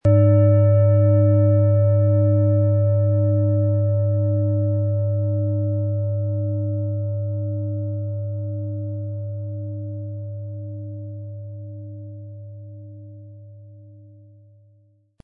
Um den Original-Klang genau dieser Schale zu hören, lassen Sie bitte den hinterlegten Sound abspielen.
Besonders schöne Töne zaubern Sie aus der Klangschale, wenn Sie sie sanft mit dem beiliegenden Klöppel anspielen.
PlanetentonUranus
MaterialBronze